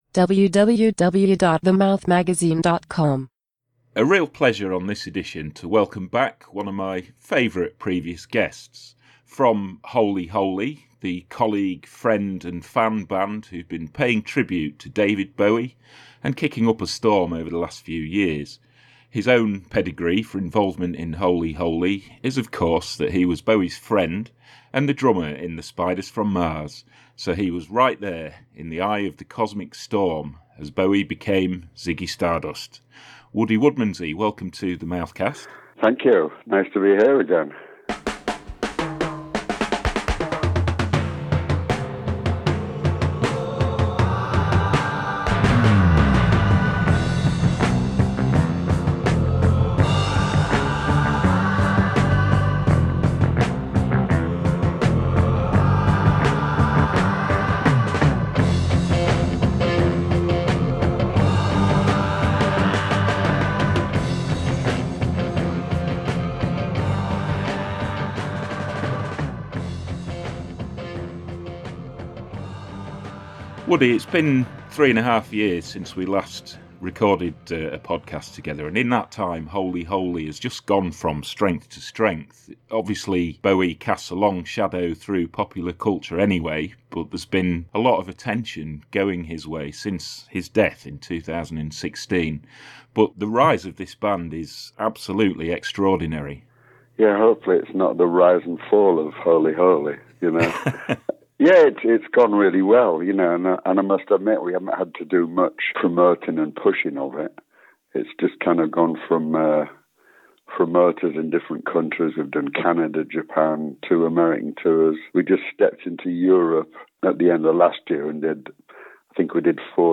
In this new edition of The Mouthcast Woody recalls The Spiders From Mars’ talismanic frontman, and offers an insight into the era when classic songs seemed to tumble out of him.